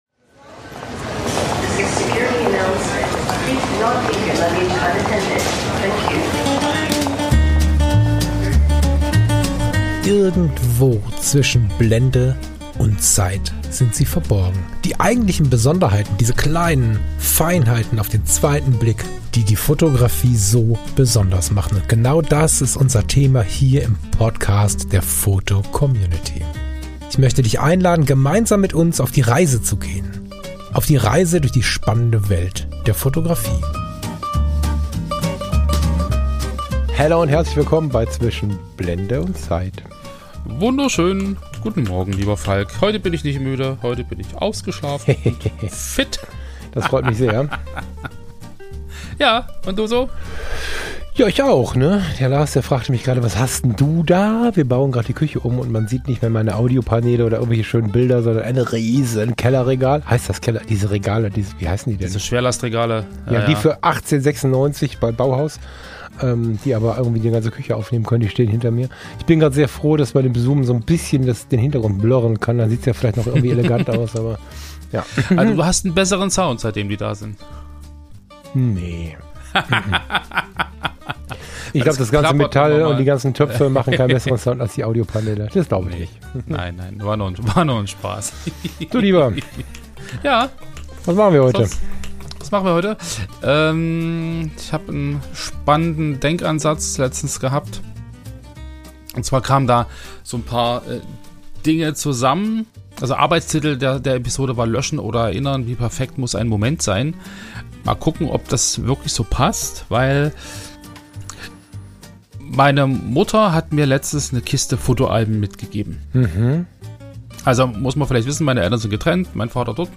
Fotografischer Mittwochs-Talk der fotocommunity